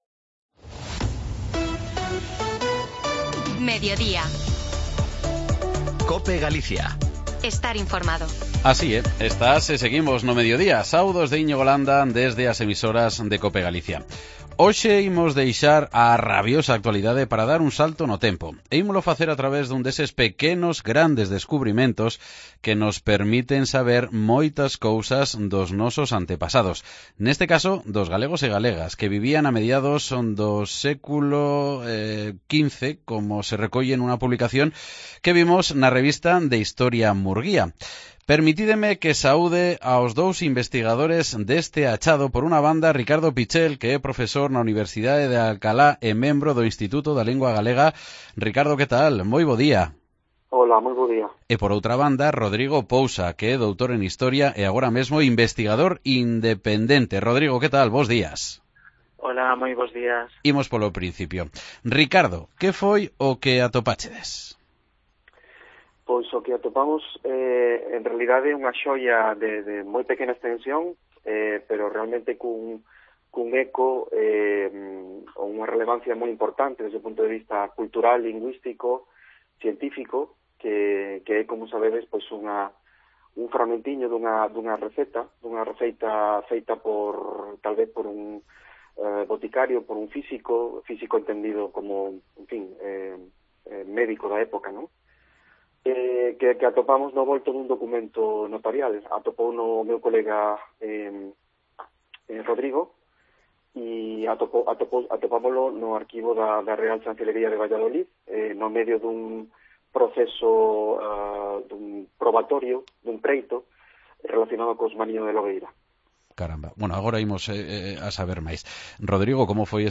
Escoita a entrevista en Mediodía Galicia